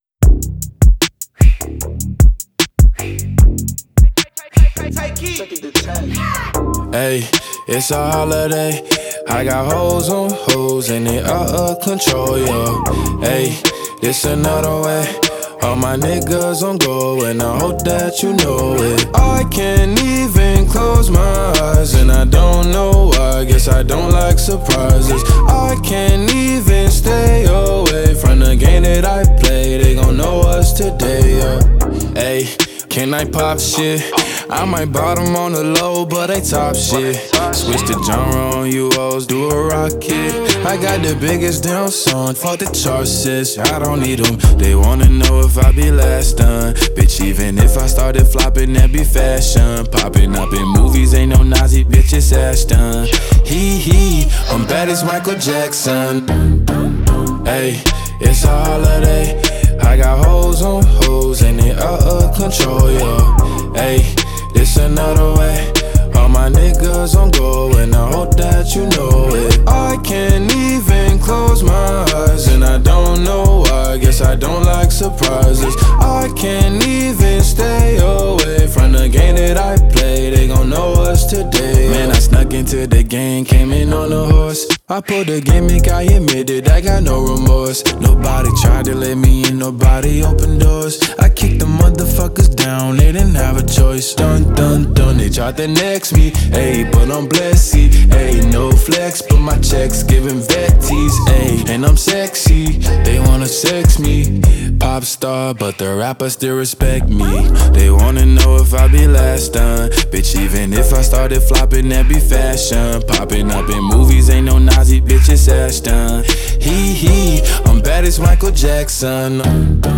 American rapper, singer, and songwriter